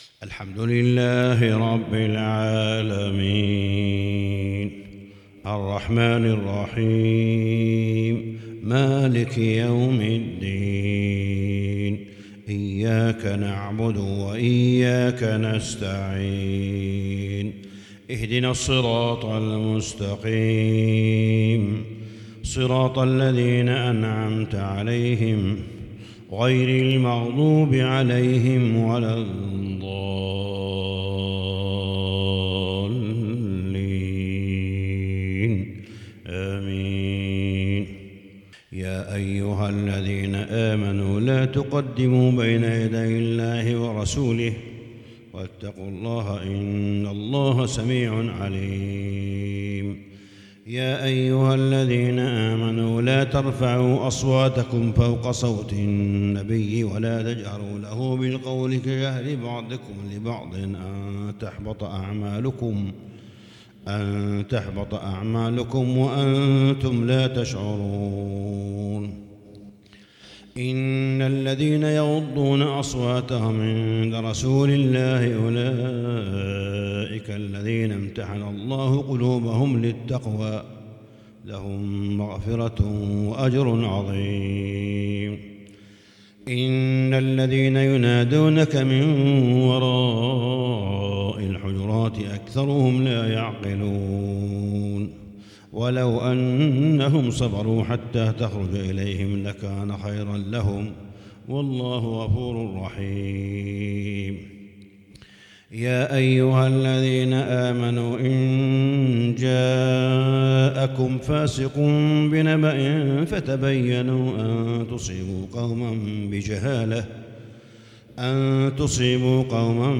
صلاة الفجر للشيخ صالح بن حميد 2 ذو الحجة 1442 هـ
تِلَاوَات الْحَرَمَيْن .